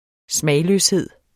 Udtale [ ˈsmæjløsˌheðˀ ]